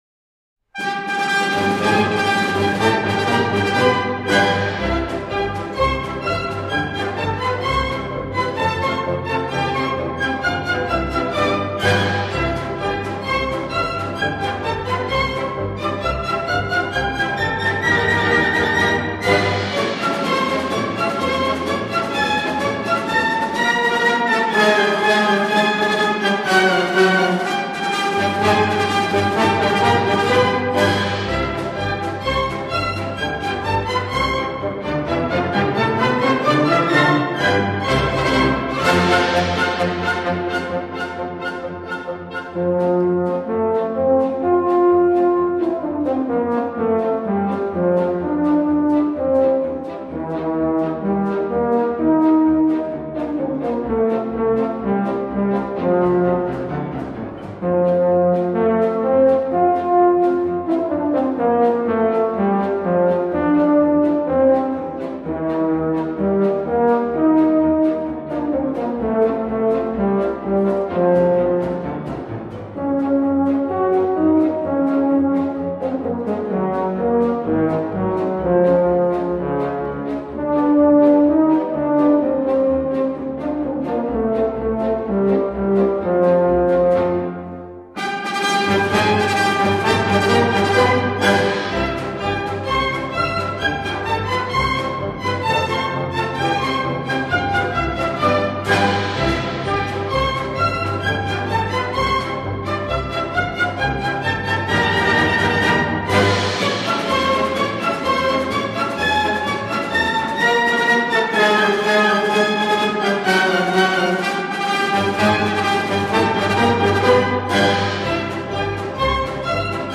ESTRUCTURA:  A-B-A
COMPÀS: 2/4
INSTRUMENT SOLISTA: La trompa.